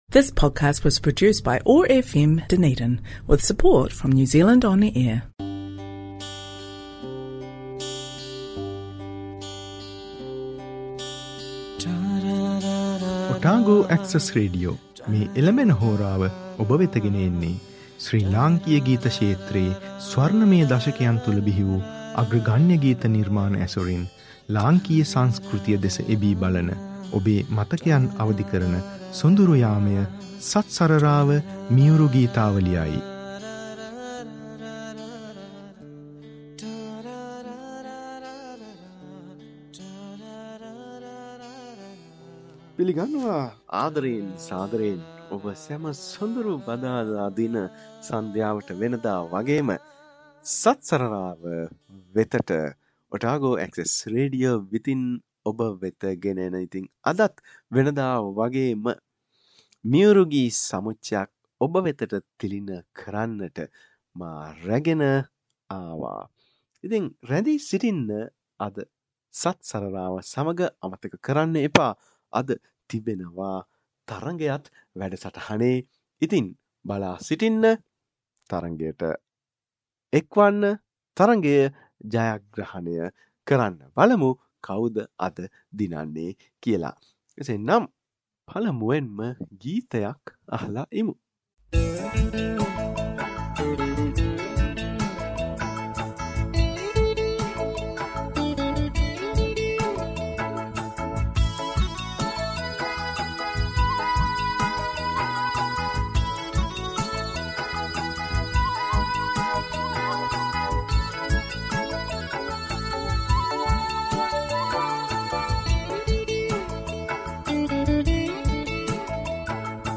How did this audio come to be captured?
This show was broadcast on OAR 105.4FM Dunedin